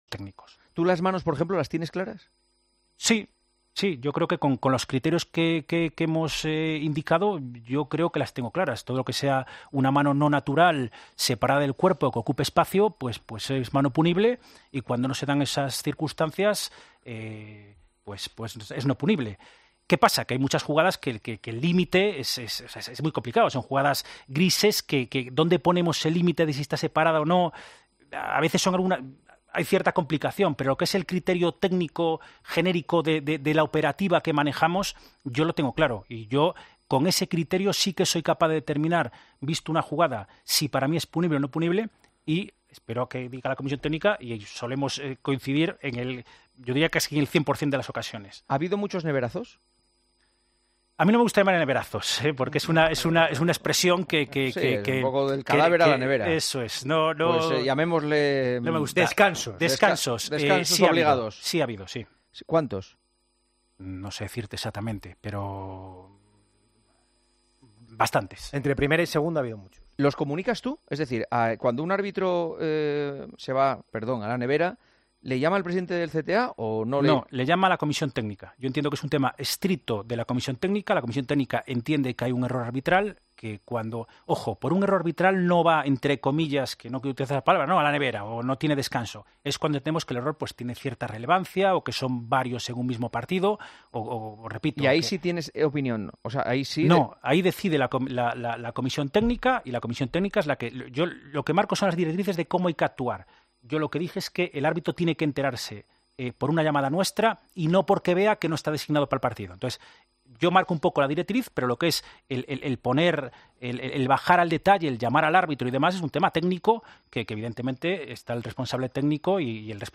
El presidente del CTA charla con Juanma Castaño sobre los 'descansos' que se les da a los árbitros por sus errores